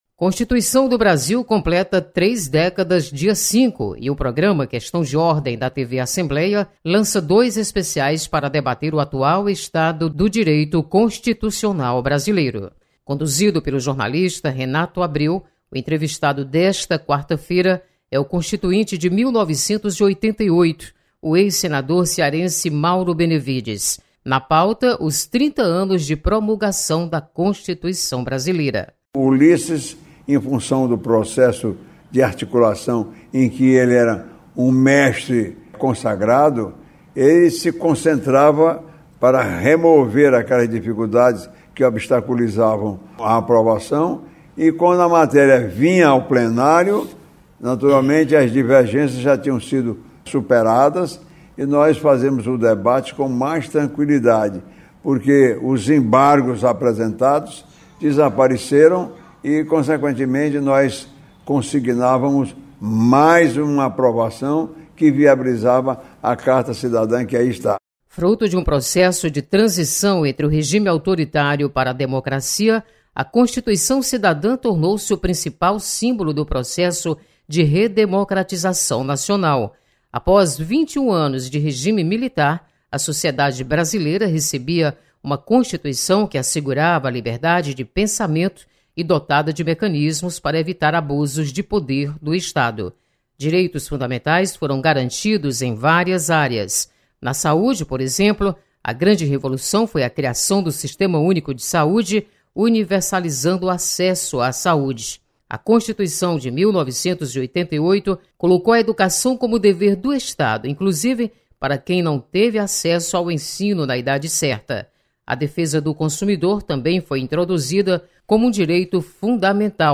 Programa Questão de Ordem recebe o constituinte Mauro Benevides.